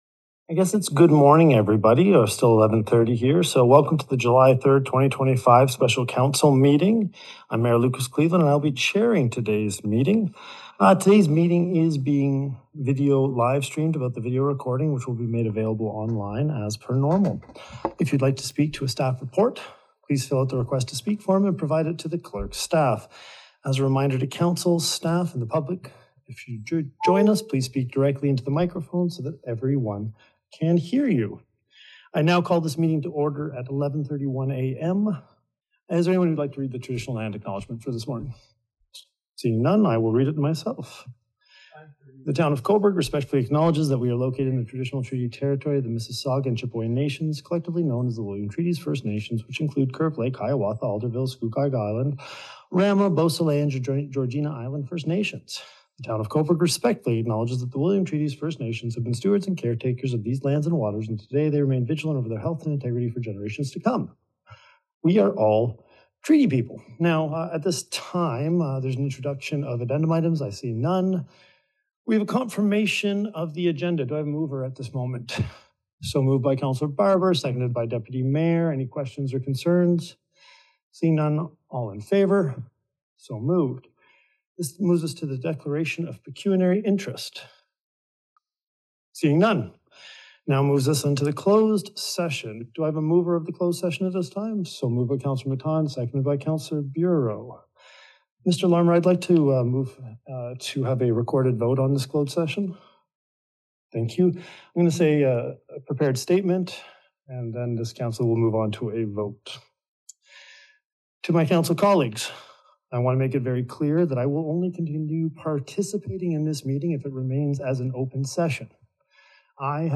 But Cleveland pre-empted efforts at the start when he read a prepared statement to councillors.
Upon resuming the open session, the council reaffirmed its commitment to a safe community and passed a confirmatory bylaw. You can listen to the statement, the discussion, the recorded vote, and the motion coming out of the closed session.